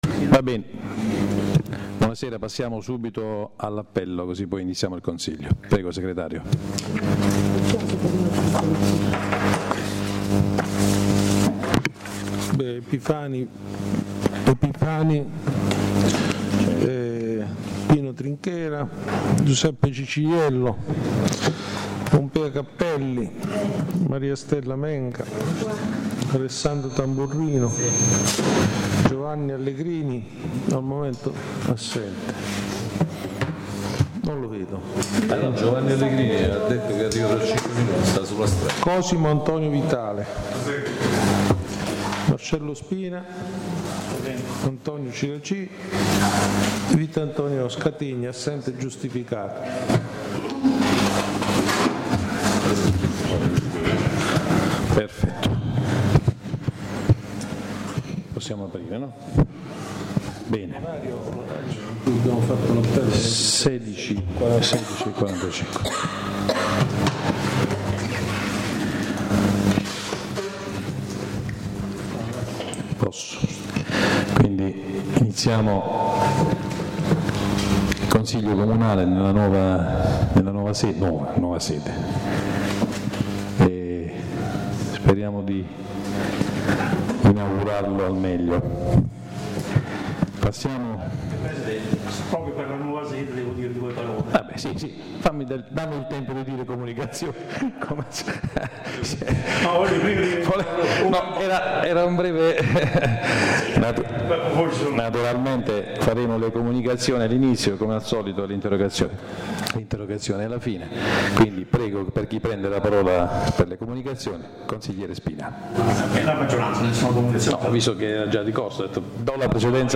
La registrazione audio del Consiglio Comunale di San Michele Salentino del 25/02/2014